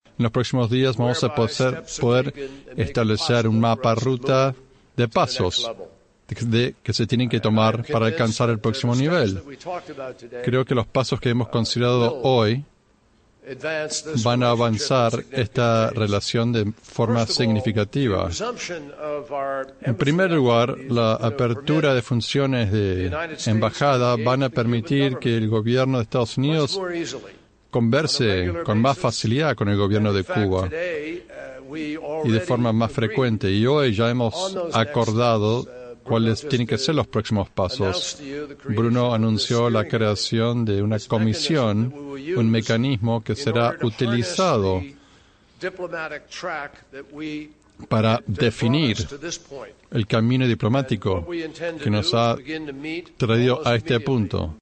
Durante la conferencia de prensa conjunta ambos funcionarios reiteraron la intención de seguir colaborando en aquellos asuntos donde parecen estar de acuerdo, al tiempo que confirmaron su interés de negociar y conversar sobre temas más escabrosos donde existen profundas diferencias, como es el caso de los Derechos Humanos, los fugitivos, las compensaciones, la devolución de la Base Naval de Guantánamo y el fin de los programas prodemocracia.